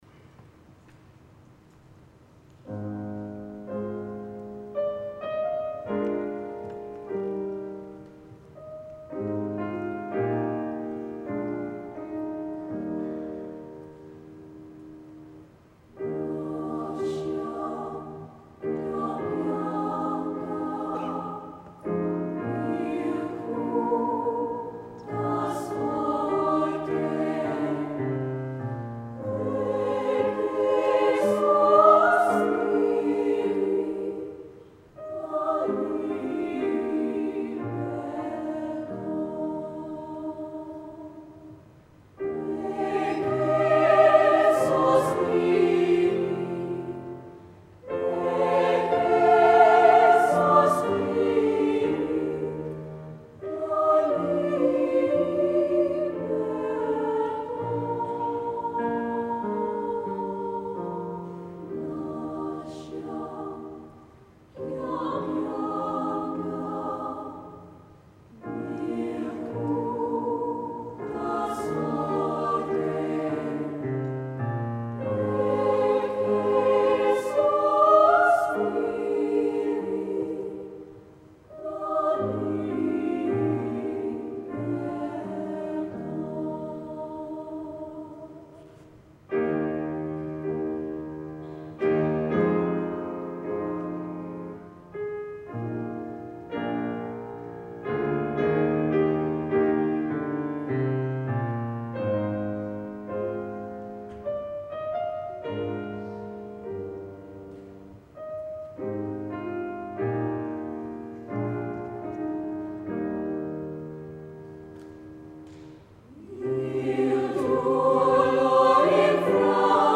Recordings from The Big Sing National Final.
Bella Voce Marlborough Girls' College Lascia Ch'io Pianga Loading the player ...